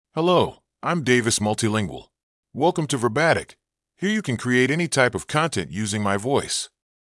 MaleEnglish (United States)
Davis MultilingualMale English AI voice
Davis Multilingual is a male AI voice for English (United States).
Voice sample
Listen to Davis Multilingual's male English voice.